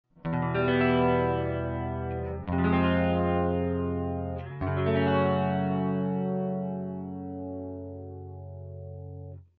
e-shape-barre-minor.mp3